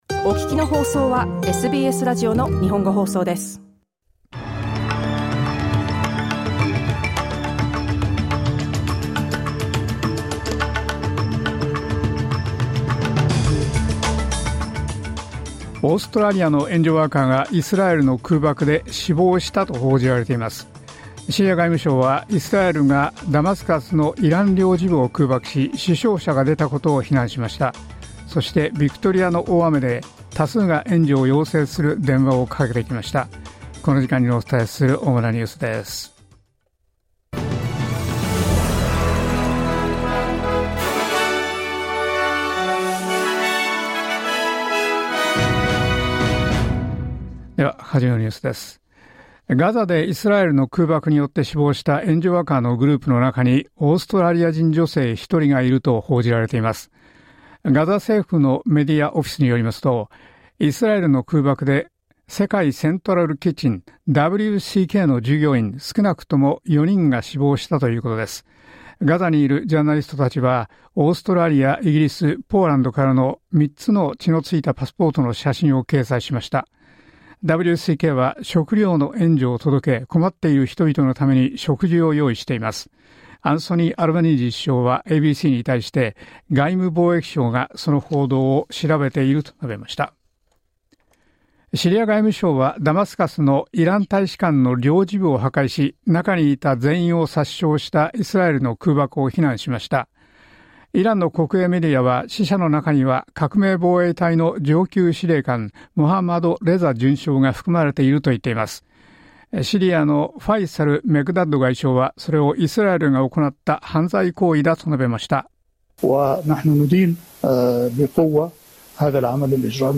SBS日本語放送ニュース４月２日火曜日